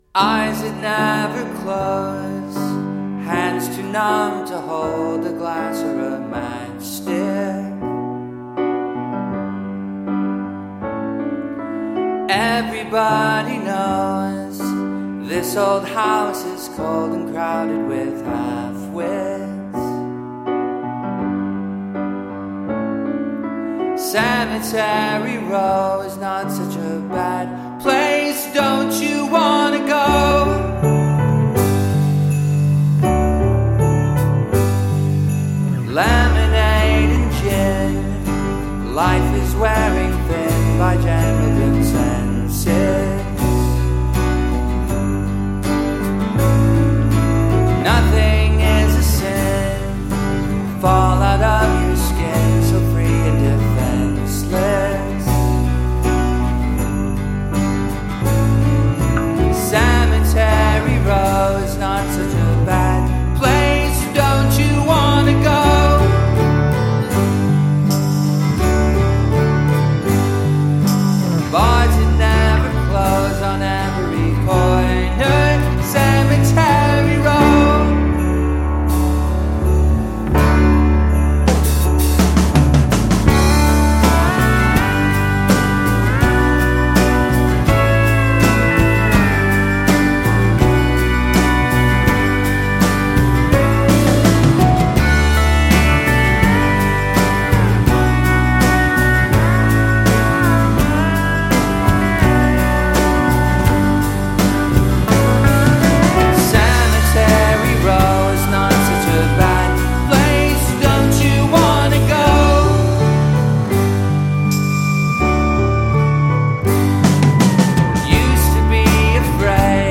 Creepy.